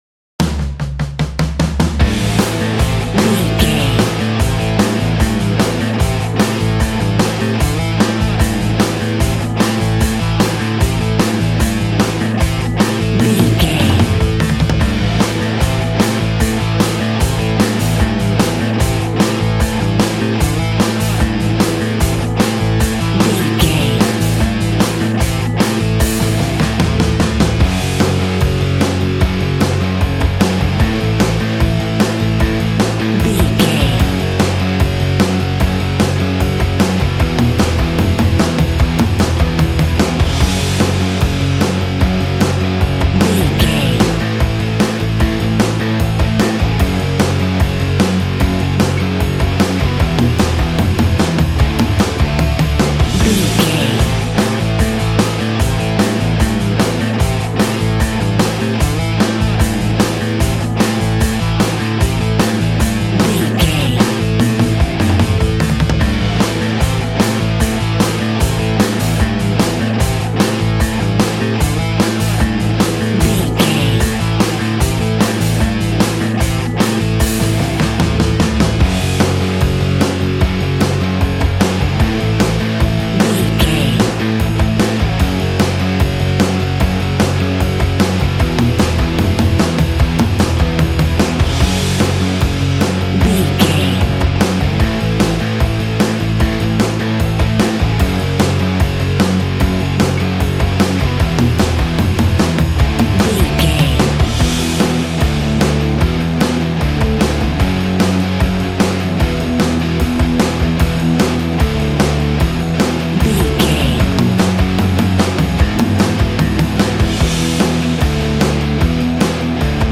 Ionian/Major
angry
aggressive
electric guitar
drums
bass guitar